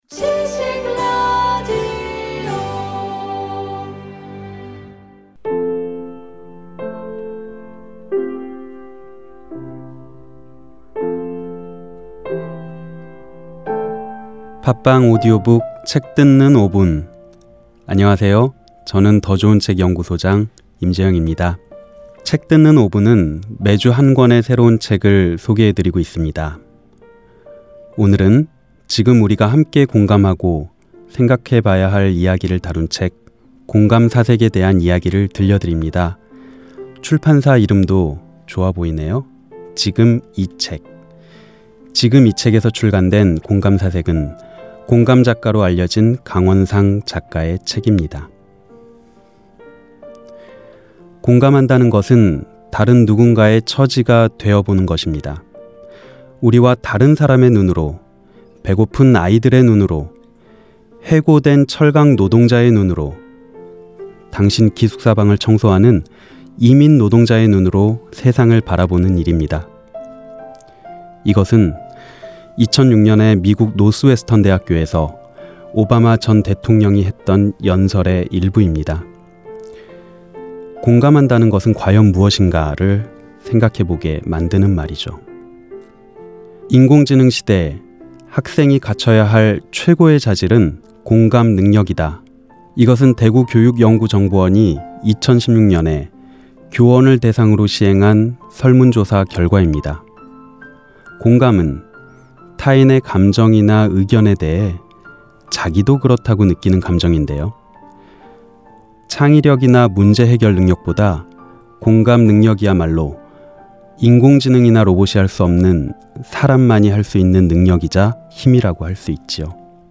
팟빵오디오북, <책 듣는 5분>